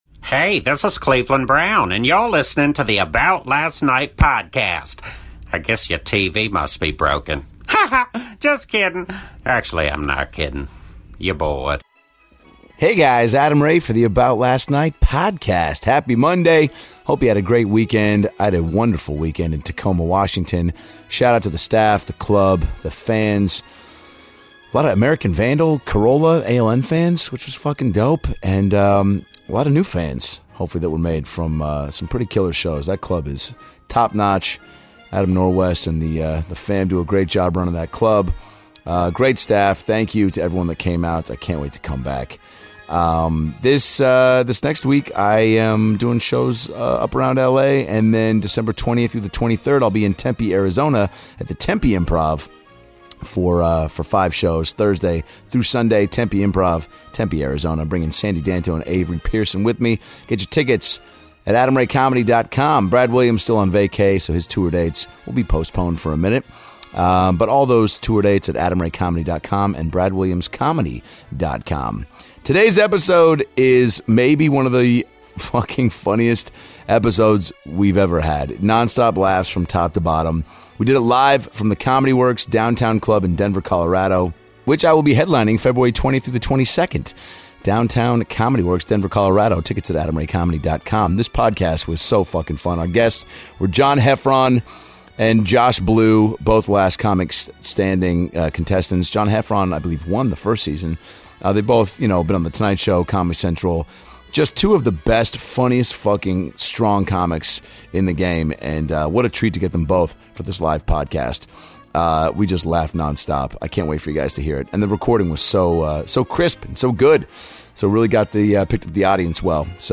ALN - LIVE from Comedy Works in Denver!